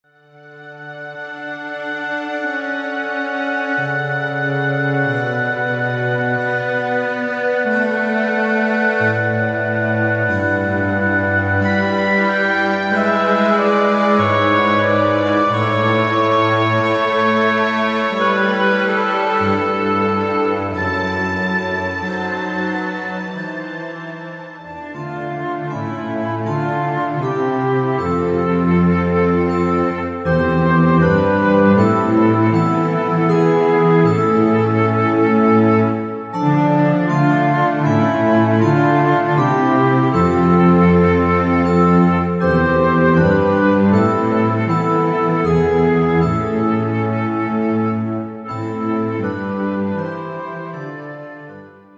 Mélodies classiques pour le dernier au revoir